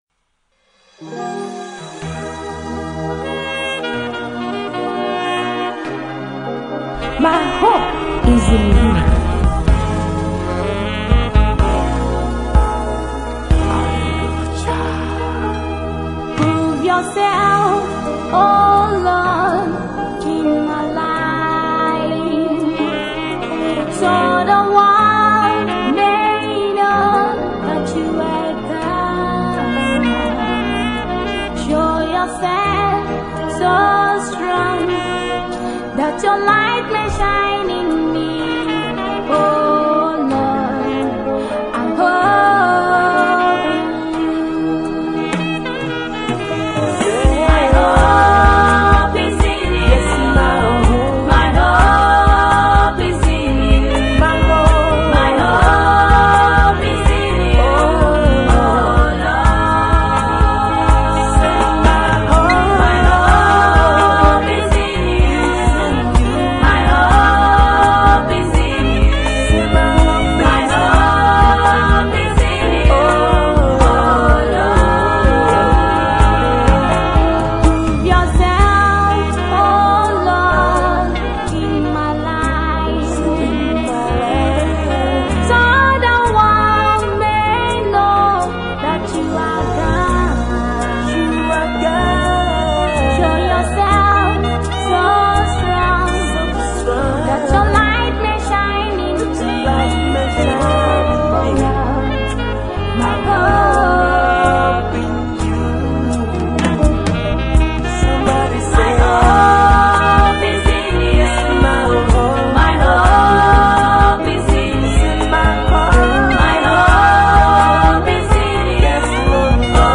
Nigerian Gospel Music